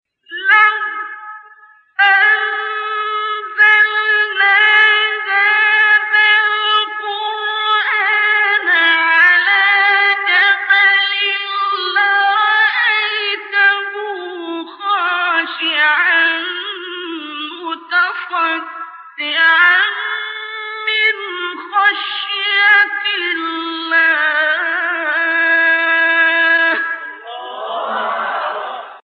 سایت قرآن کلام نورانی -حجاز منشاوی (5).mp3